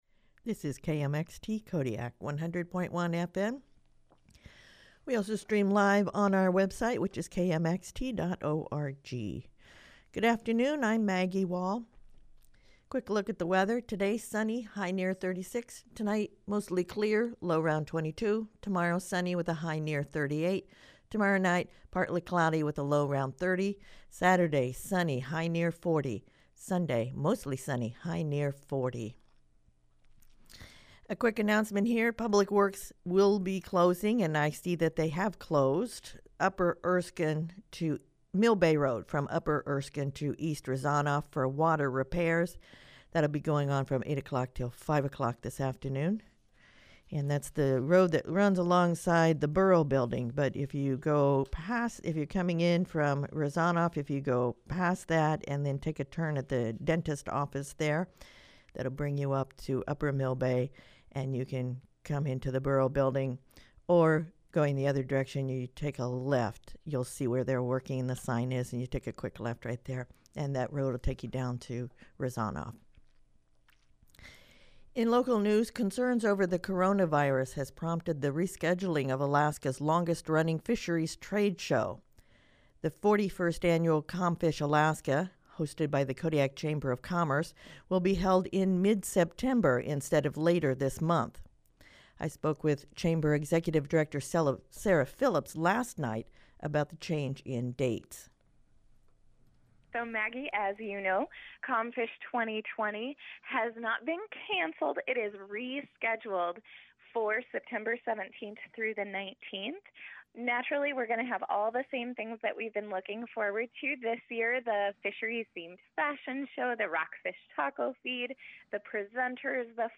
Newscast — Thursday, March 12, 2020